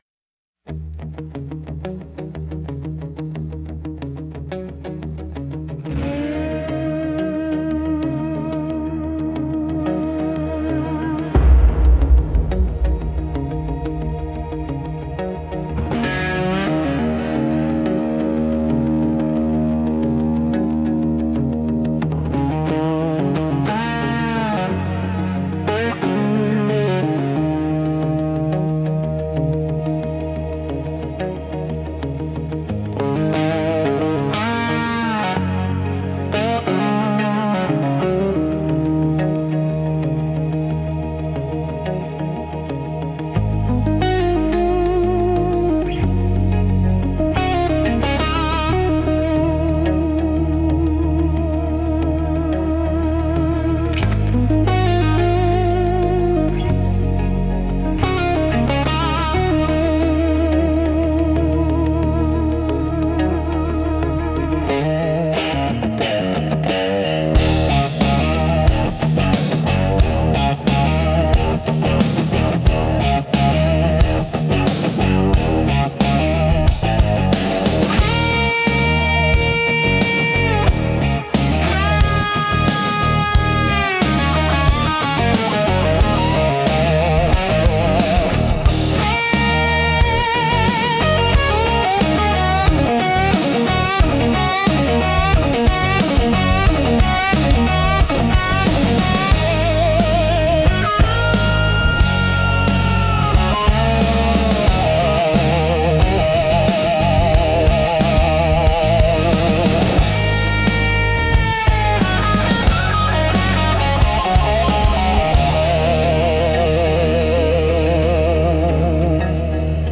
Instrumental - Dusk Resonance 2.57